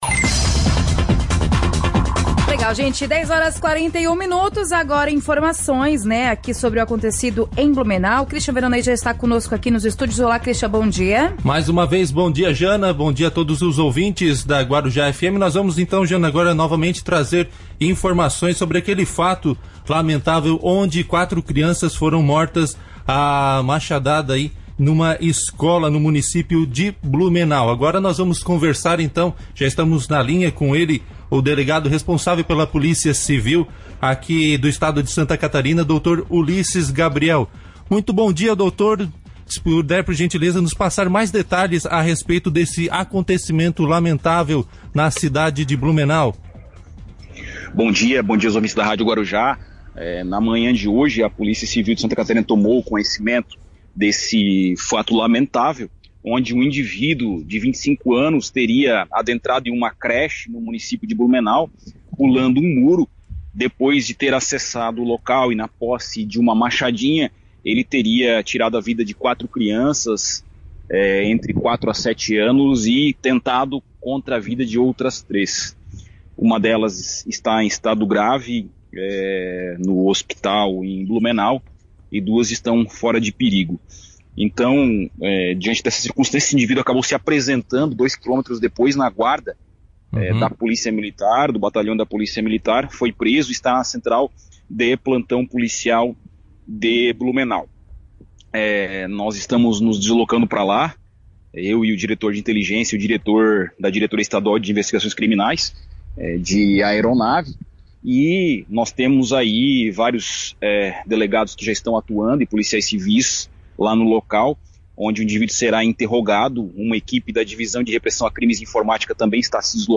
Em entrevista à rádio Guarujá, o delegado geral da Polícia Civil Ulisses Gabriel deu detalhes sobre o atentado à creche em Blumenau que resultou na morte de 4 crianças e que deixou outras feridas.